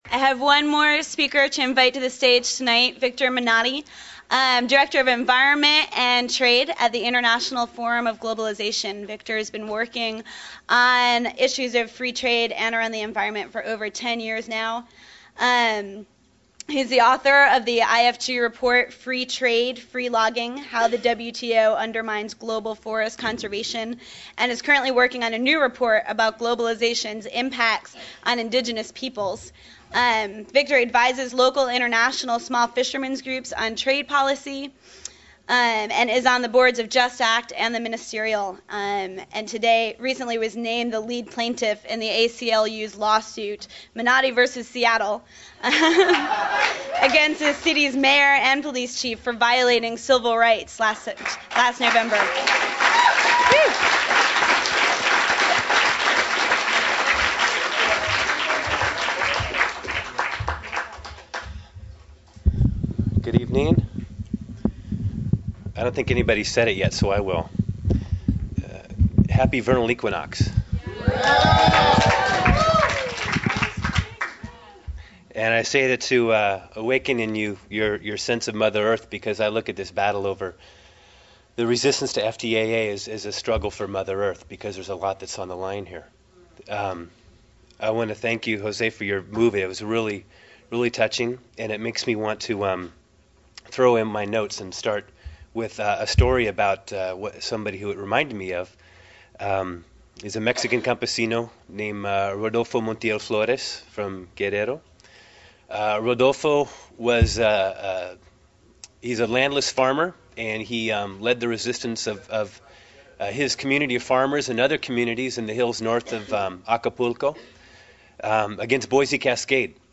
Berkeley FTAA Teach-In: Environmental Impacts; Buenos Aires A07